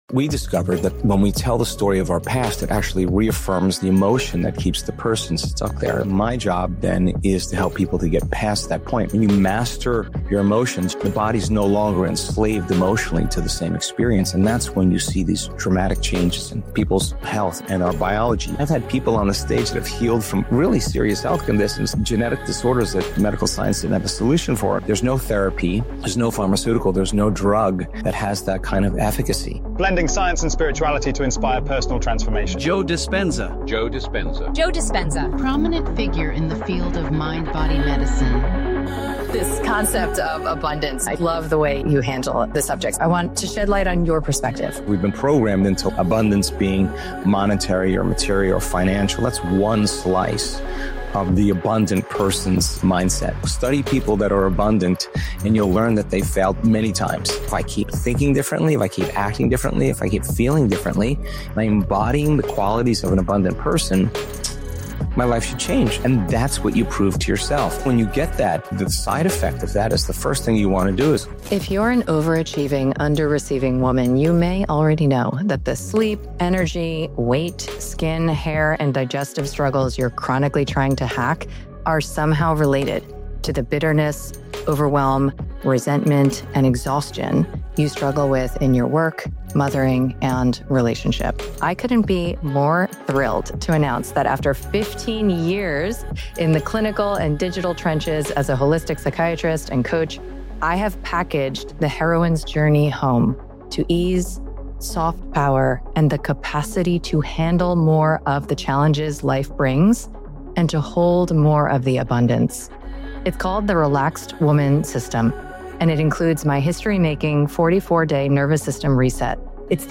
In this conversation with Dr. Joe Dispenza, I share what happened when I attended one of his in-person retreats.